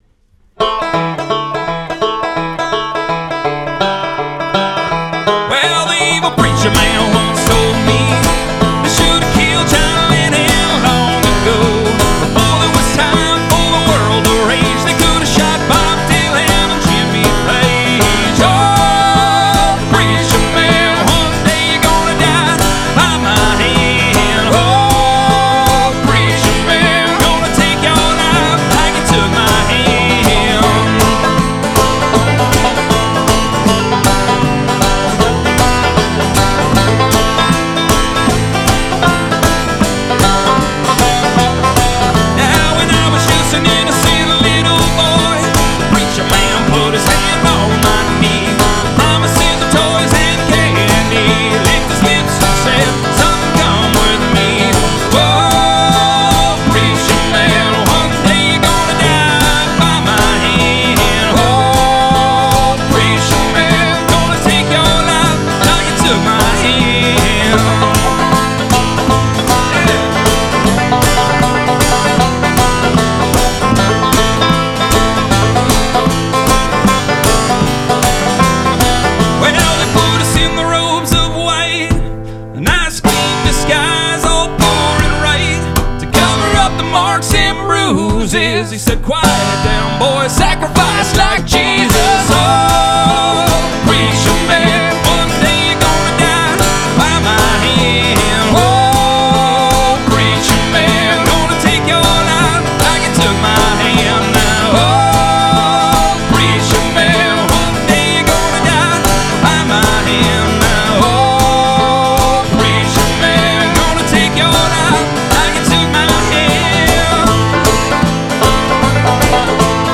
murder Bluegrass and Punk Folk
Recorded live in studio, must have CD for your collection!